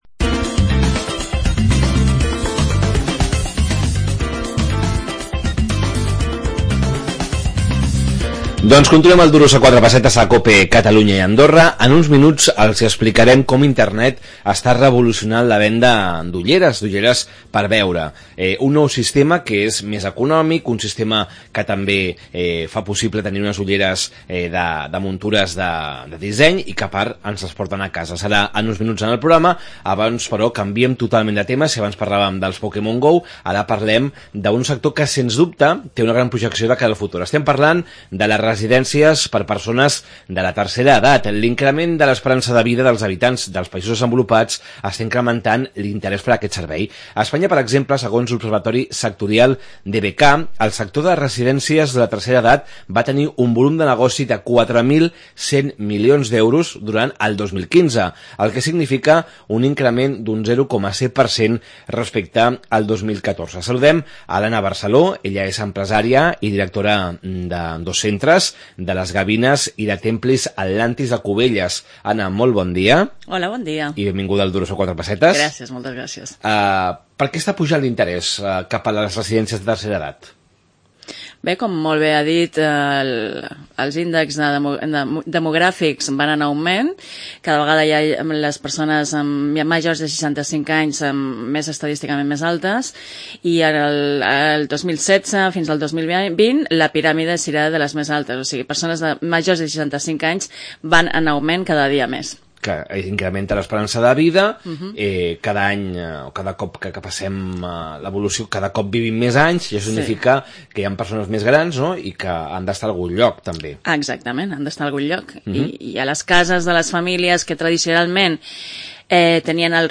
El sector de les residències de la tercera edat a Catalumya. Any rere any incrementa el seu volum de negoci. Entrevista